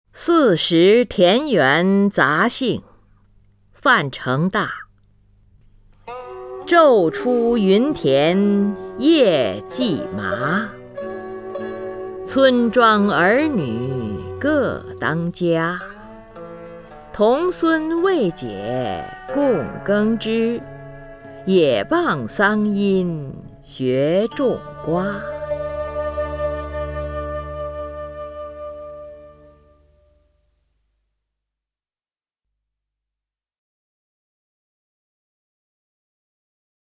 林如朗诵：《夏日田园杂兴·其七》(（南宋）范成大)
名家朗诵欣赏 林如 目录